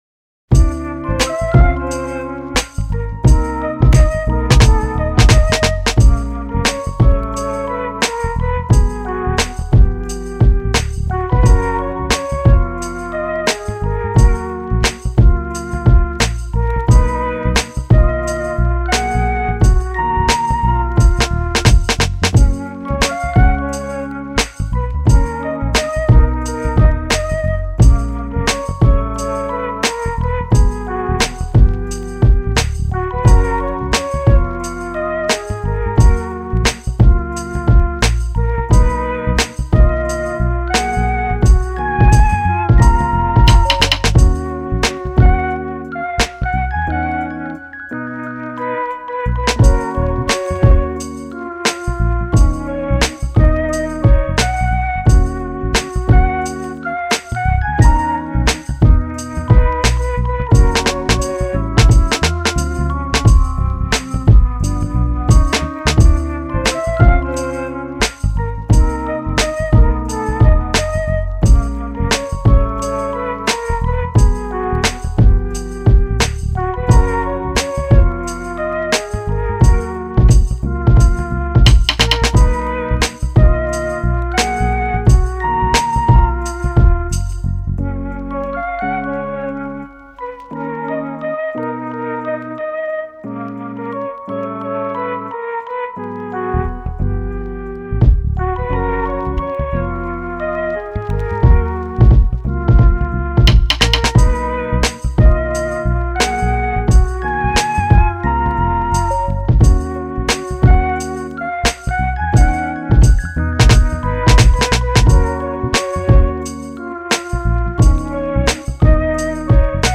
チル・穏やか フリーBGM